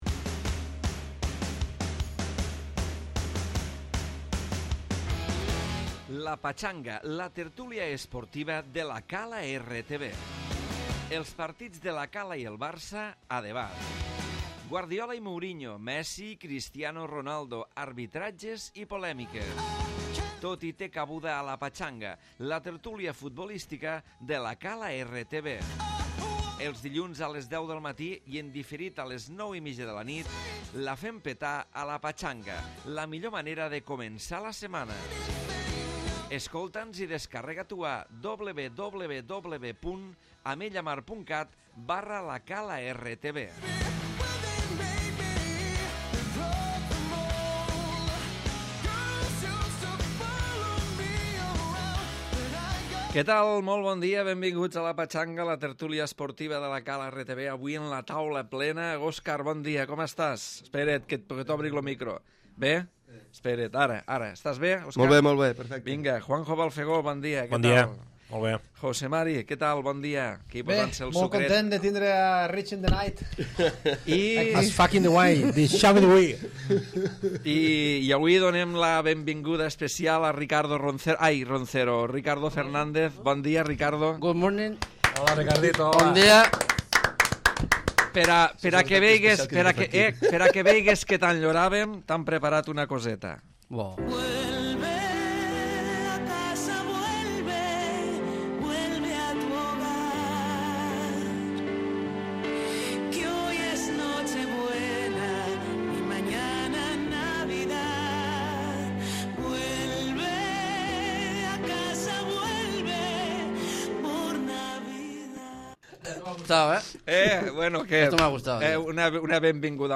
Tertúlia esportiva